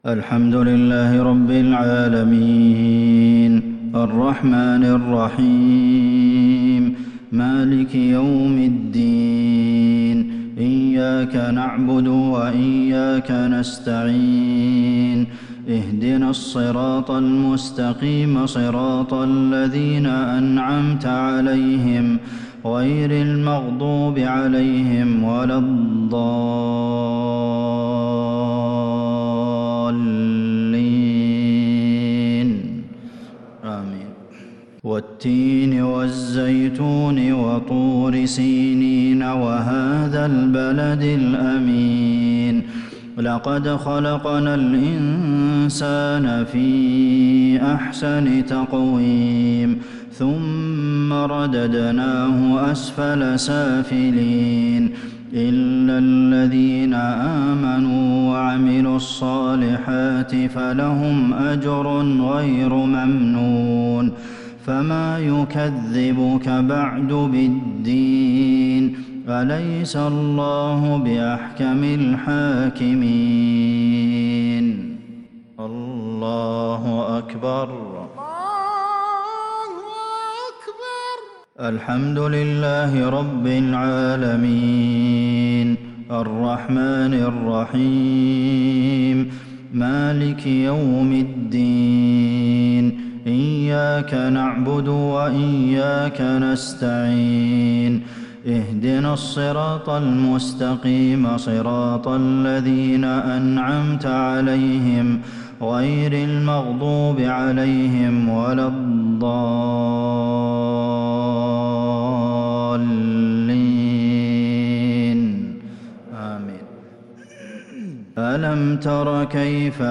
صلاة المغرب للشيخ عبدالمحسن القاسم 8 ربيع الآخر 1442 هـ
تِلَاوَات الْحَرَمَيْن .